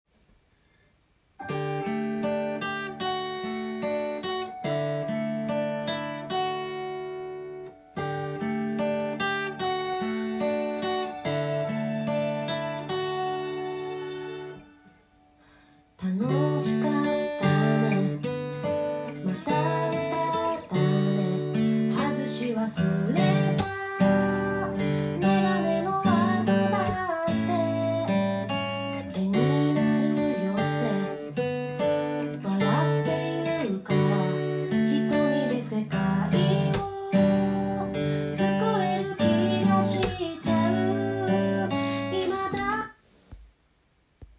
音源にあわせて譜面通り弾いてみました
指弾きまたはピックと指のハイブリット奏法で弾くと音源のような雰囲気を出せます。
右手はアルペジオ主体になるので、少し弾くにくくなります。